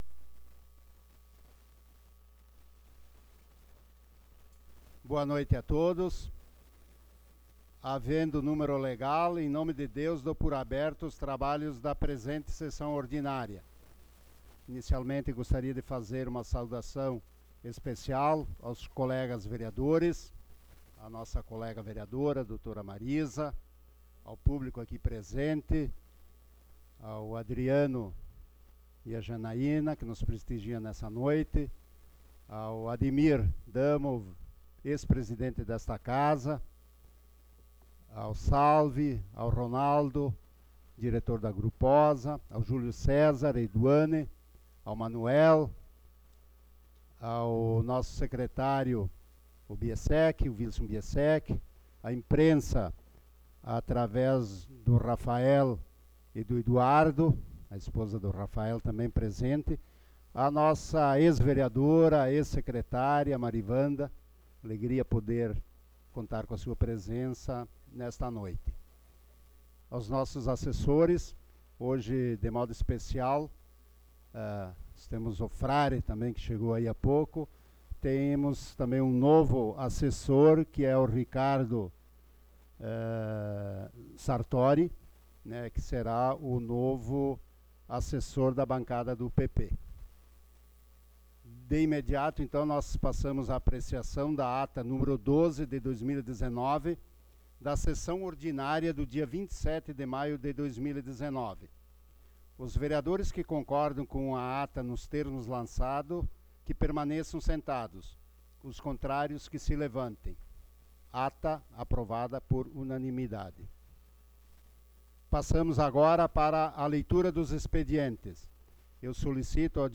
Sessão Ordinária do dia 03 de Junho de 2019